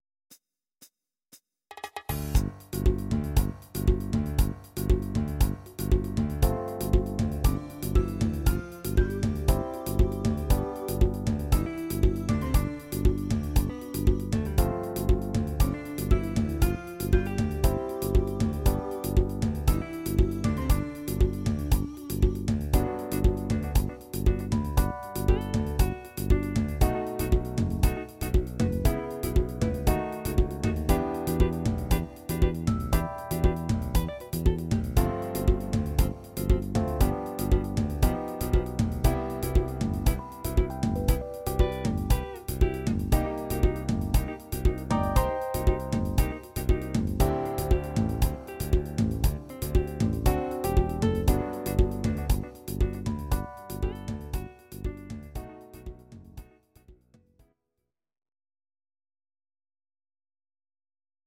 Audio Recordings based on Midi-files
Our Suggestions, Pop, 1970s